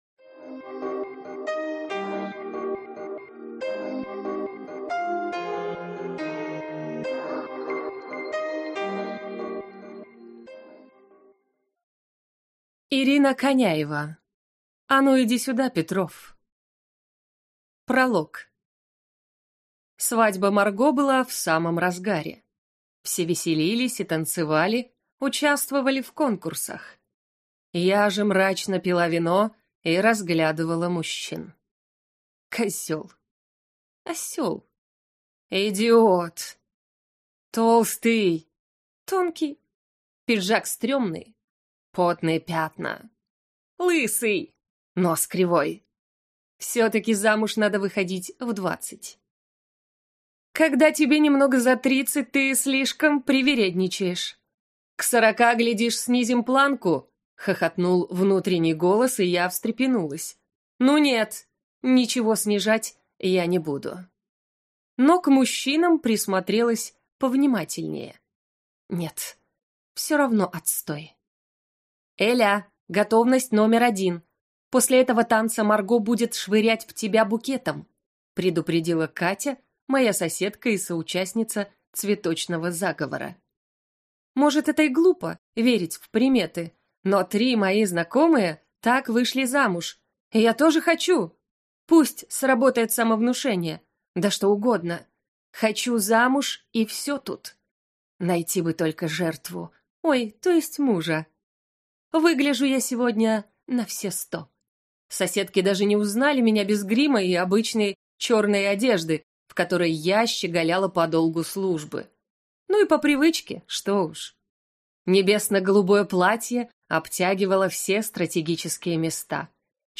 Аудиокнига А ну, иди сюда, Петров!